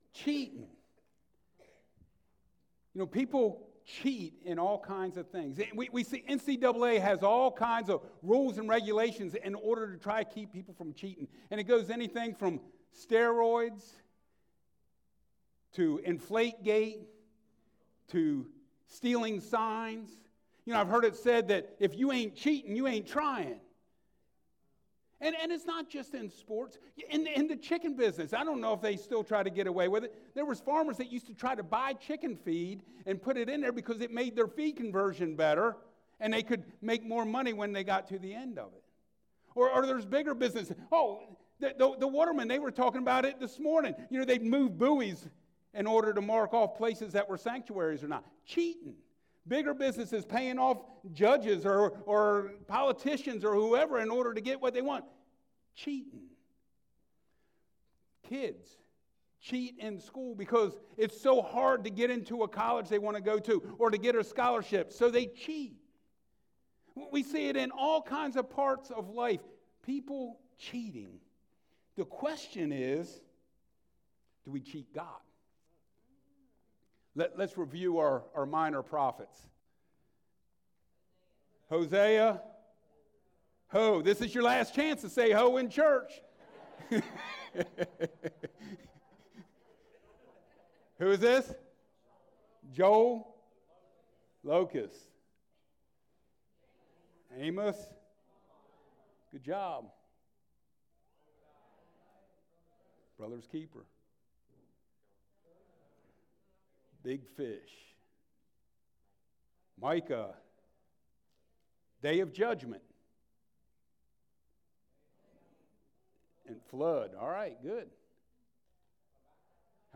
Malachi 3 Service Type: Sunday Mornings A Study of Old Testament Prophets